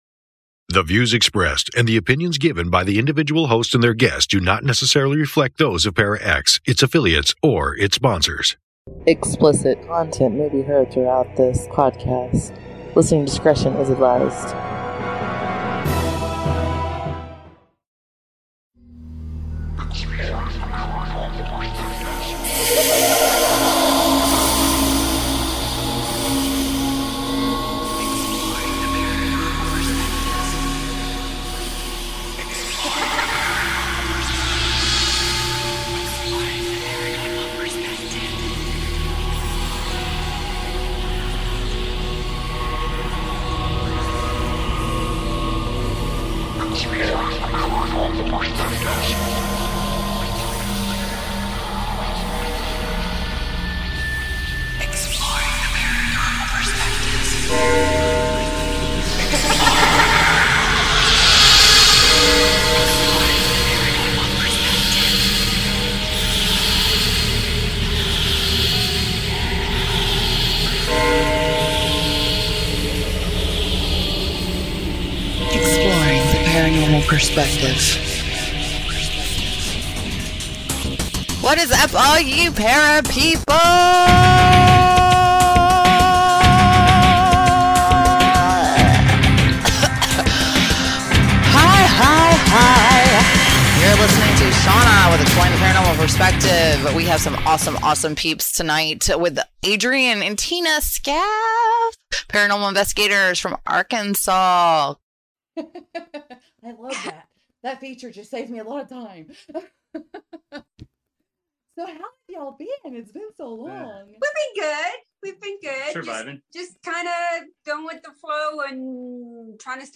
E.P.P. S01 EP03 EPP's interview w/